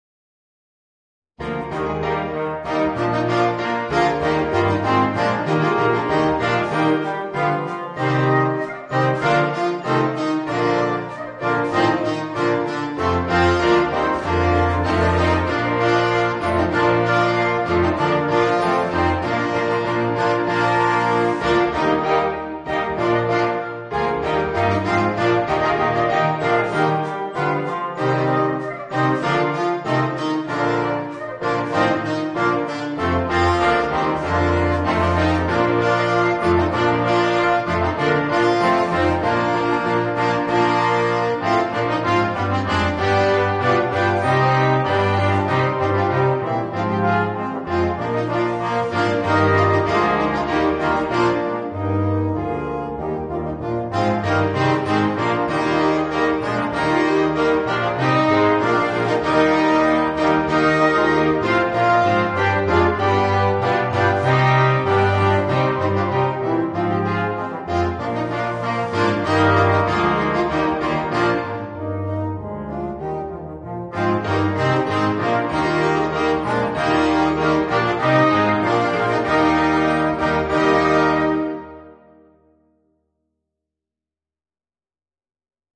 Voicing: 8 - Part Ensemble